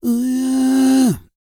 E-CROON 3048.wav